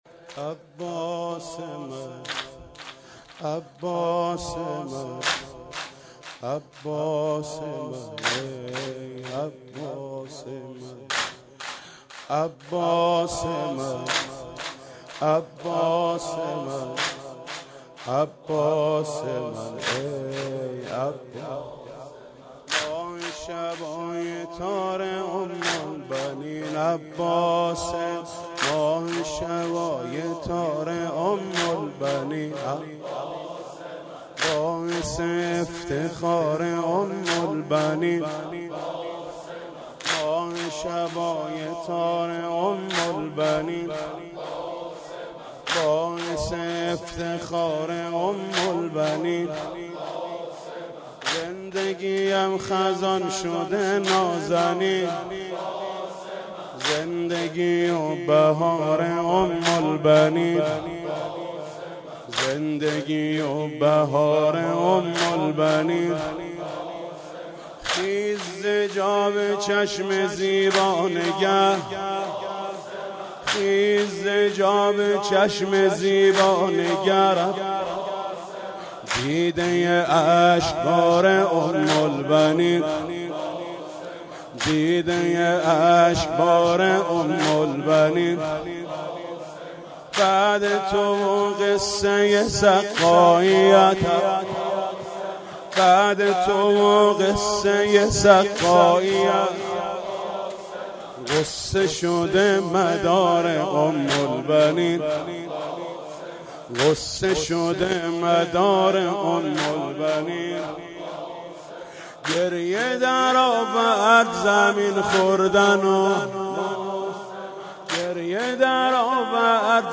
صوت/ مداحی محمود کریمی؛ وفات ام البنین(س)